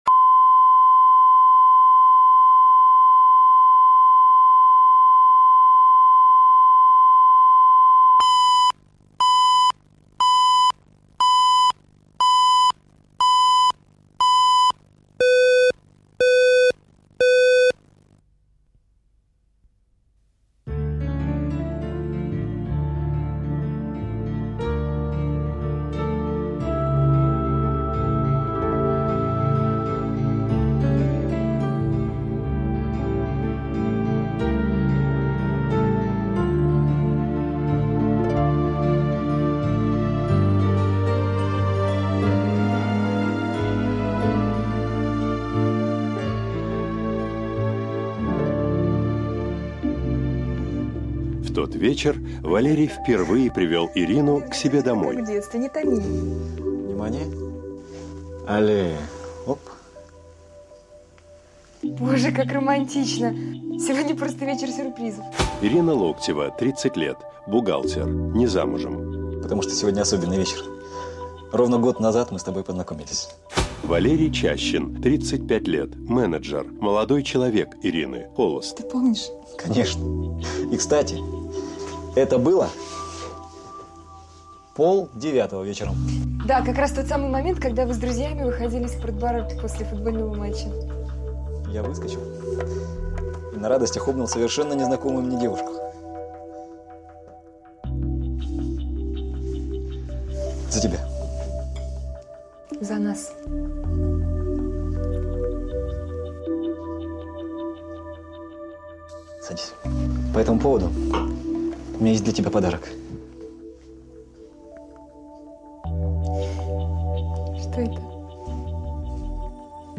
Аудиокнига В погоне за кольцом | Библиотека аудиокниг